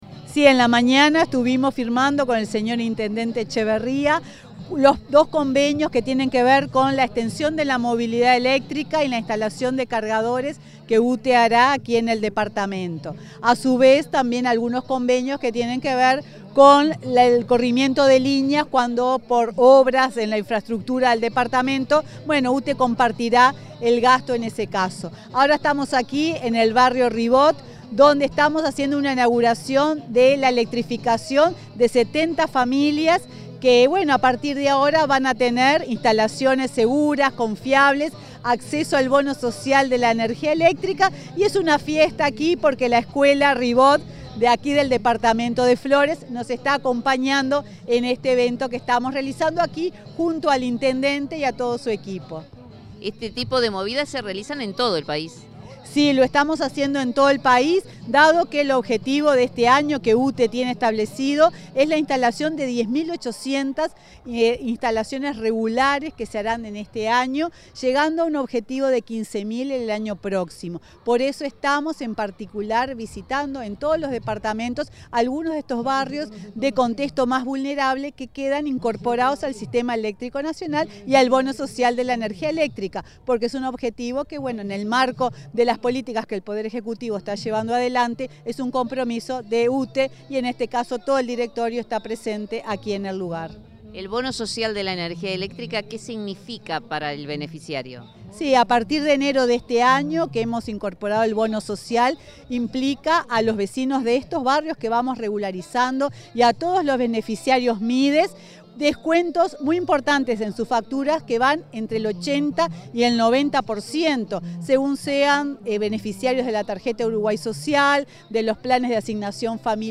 Entrevista a la presidenta de UTE, Silvia Emaldi, en Flores
Firmaron un convenio con la intendencia departamental, visitaron la oficina comercial en Trinidad e inauguraron obras de electrificación en el barrio Ribot. Luego, la presidenta de la empresa estatal dialogó con Comunicación Presidencial.